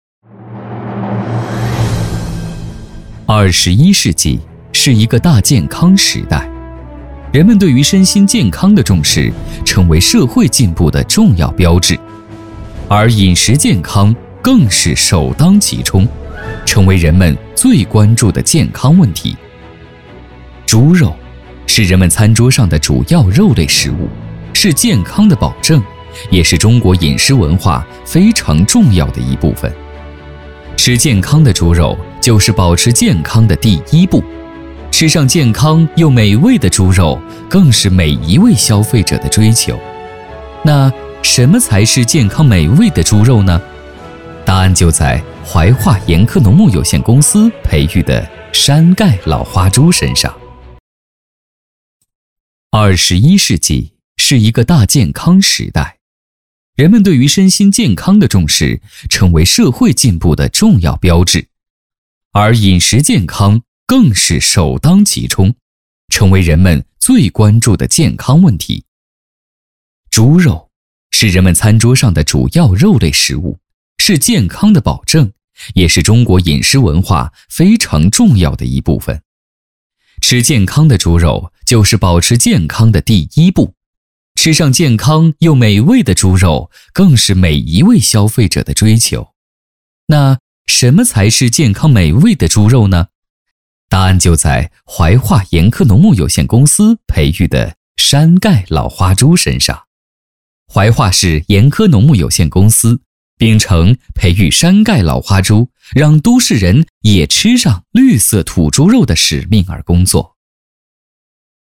擅长：专题片 广告
特点：大气浑厚 稳重磁性 激情力度 成熟厚重
风格:浑厚配音